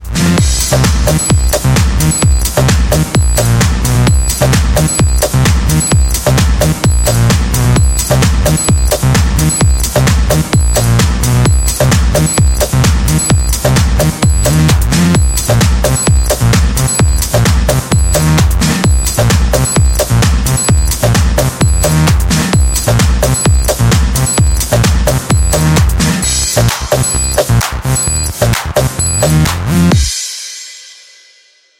Мелодии на звонок